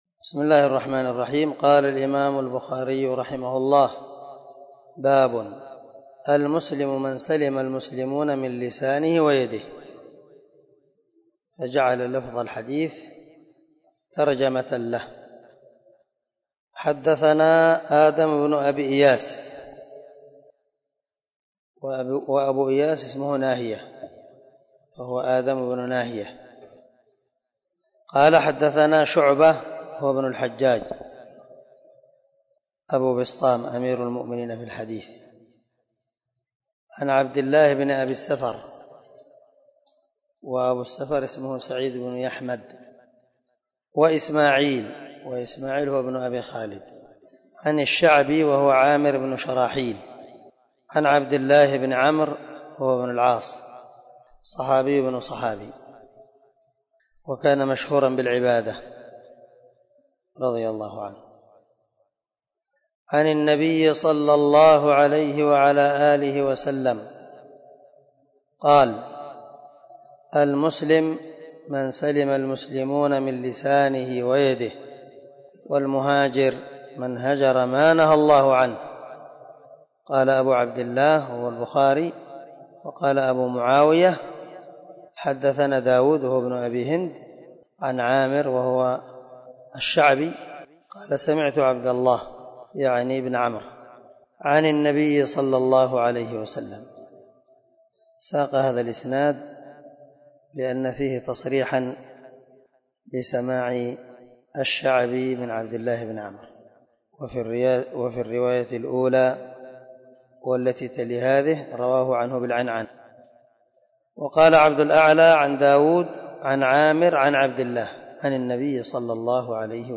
سلسلة_الدروس_العلمية
دار الحديث- المَحاوِلة- الصبي